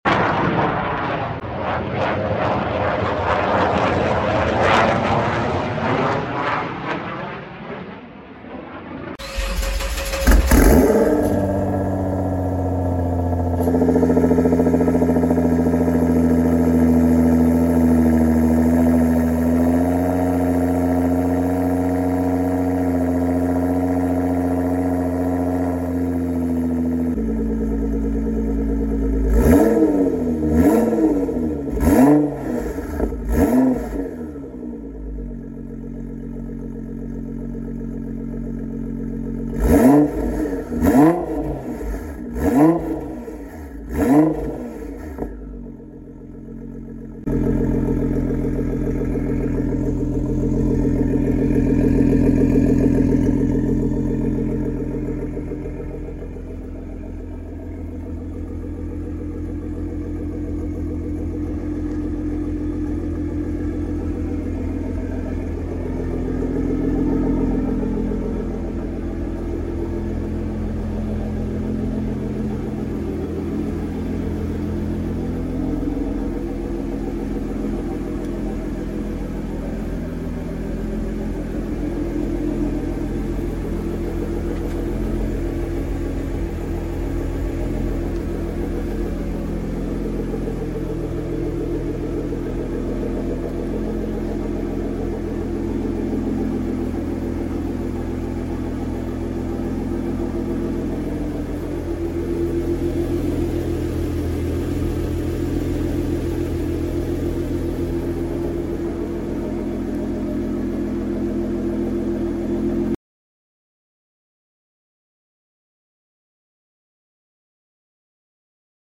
Sound 🥰 Ansicht 💪 Nissan sound effects free download